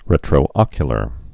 (rĕtrō-ŏkyə-lər)